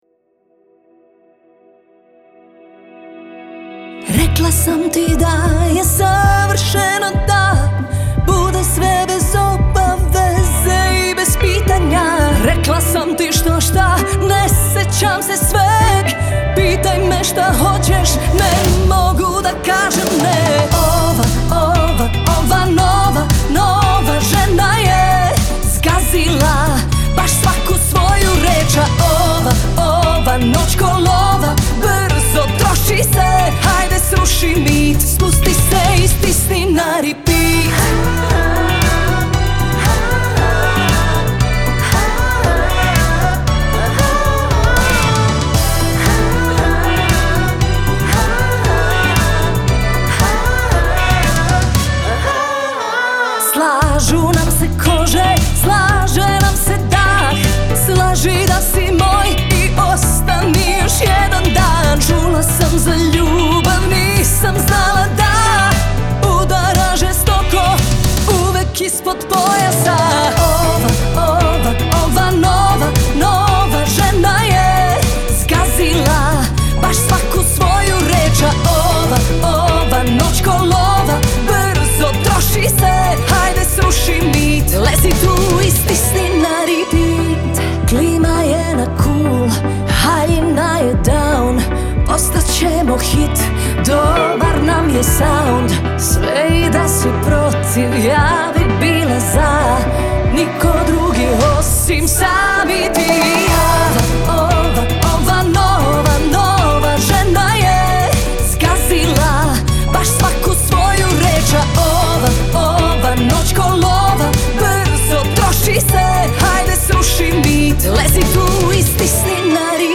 zarazni ljetni hit